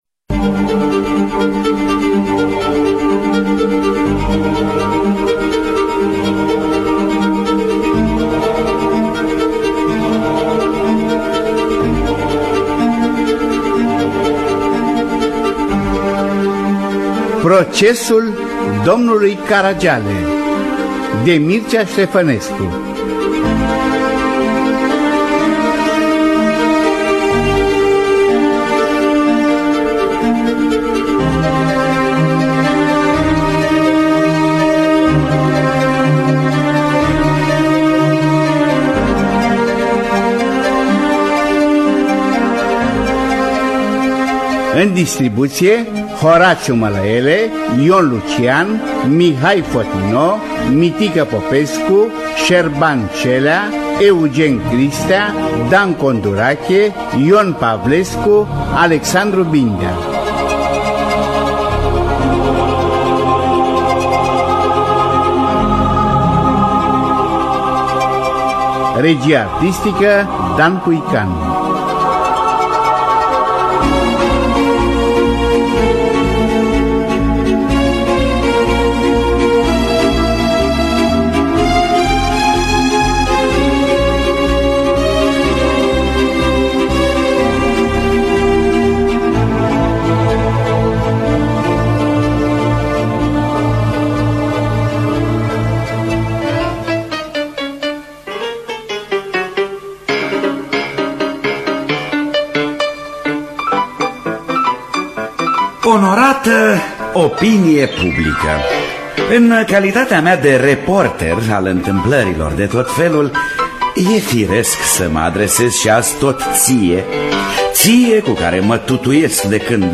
Adaptarea radiofonică
Regia de studio
Regia muzicală